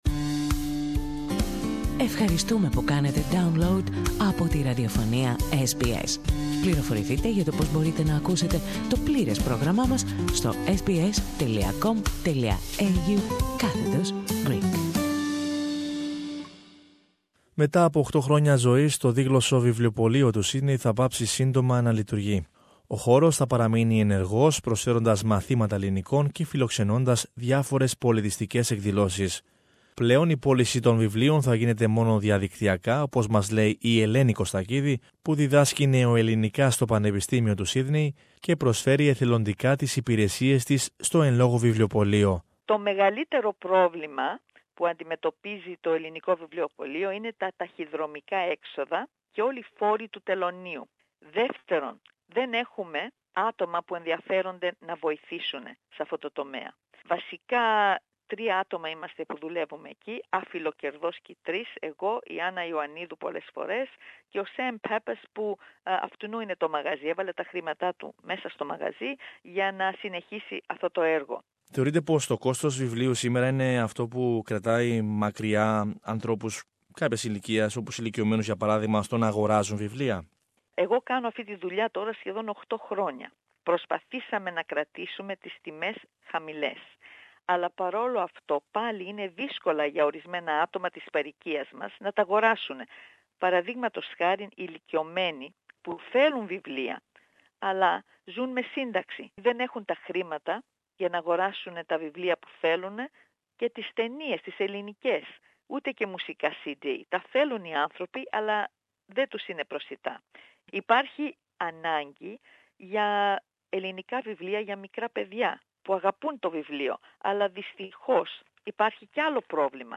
Περισσότερα ακούμε στην συνομιλία